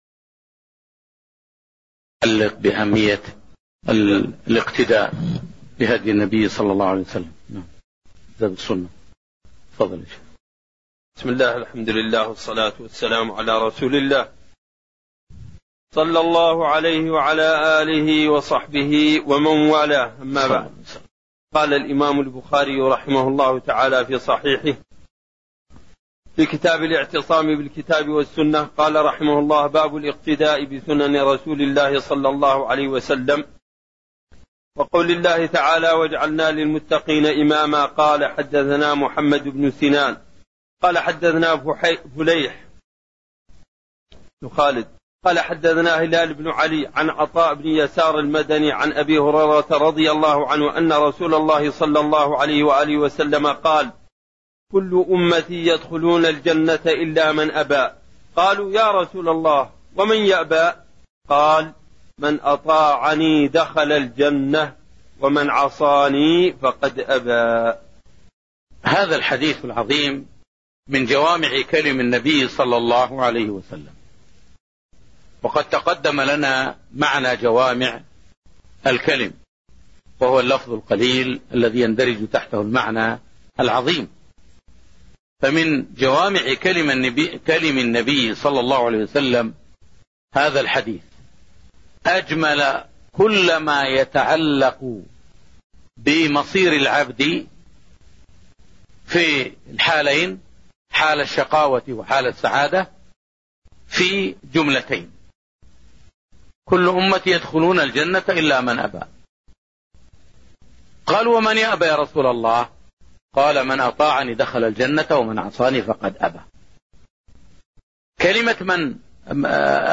تاريخ النشر ٢ ربيع الأول ١٤٣١ هـ المكان: المسجد النبوي الشيخ